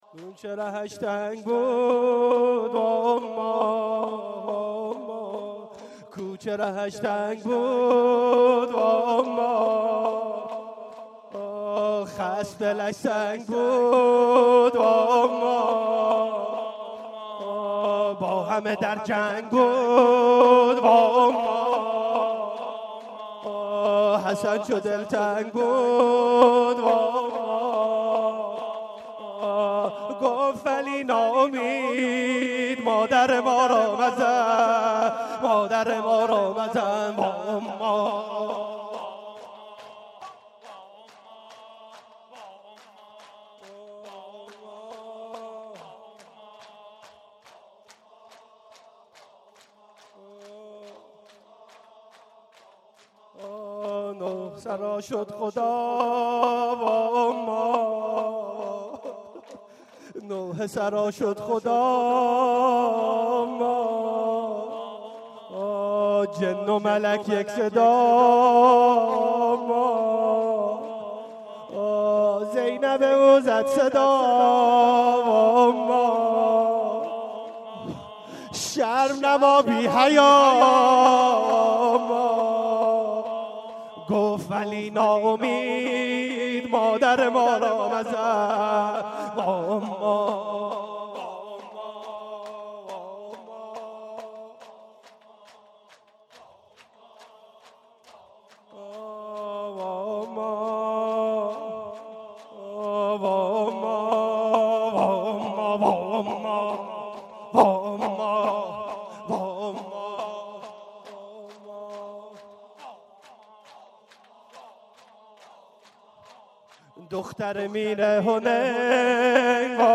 شب اول فاطميه 95 - هيئت مصباح الهدی - كوچه رهش تنگ بود